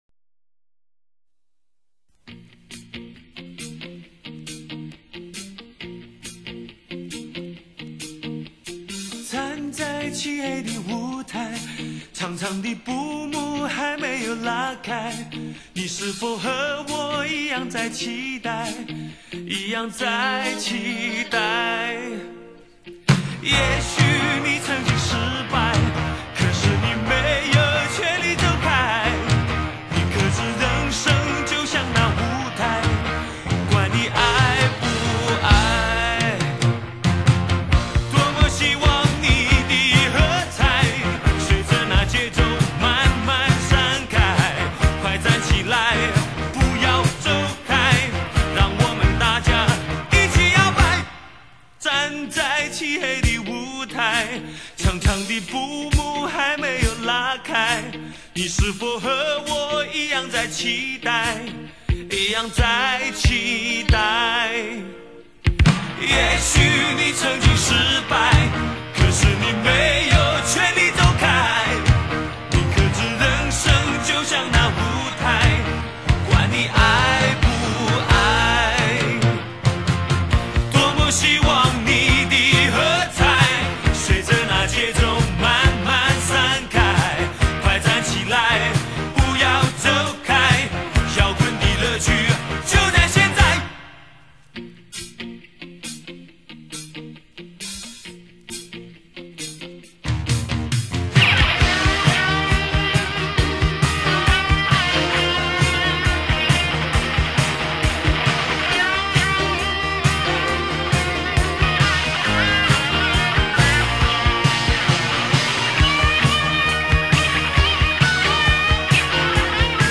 是一首很简洁，甚至可以说是很干净的摇滚歌曲
你听吧，专辑的编曲如今听起来已经是那么老套，歌词也算不上字字珠玑，但它却依然能让你觉得神彩飞扬。